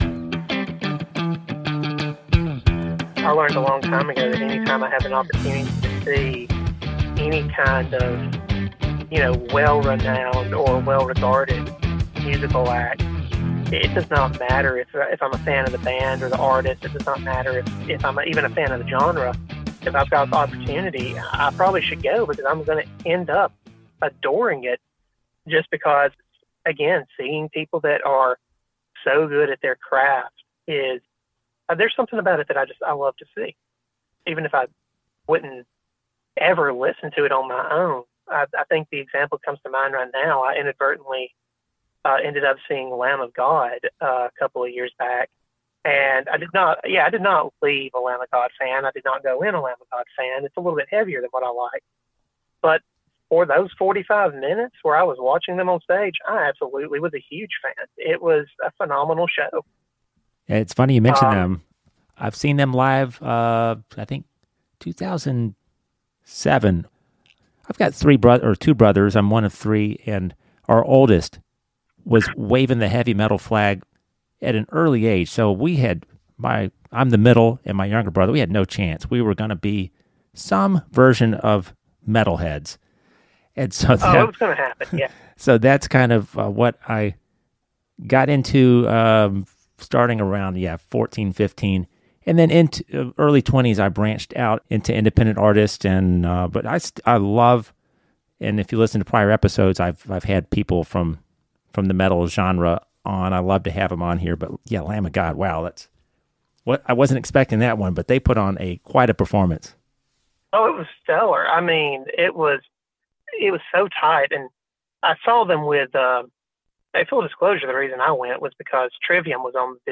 The ONLY weekly interview podcast of its kind.